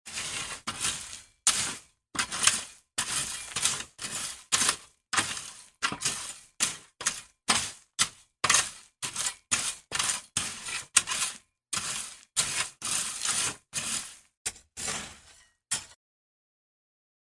Звуки граблей
На этой странице собраны реалистичные звуки граблей – от мягкого шелеста листьев до характерного скрежета по земле.
Шуршание садовых граблей на дачном участке